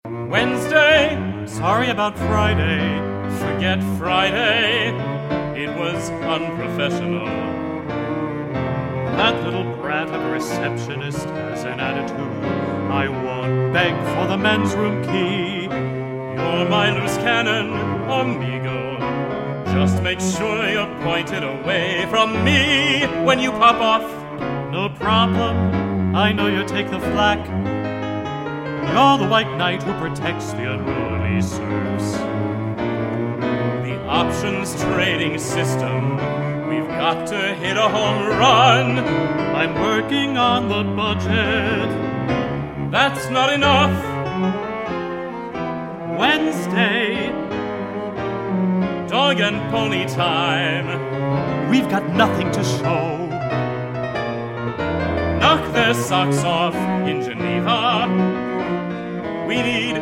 cello
baritone
soprano
synthesizer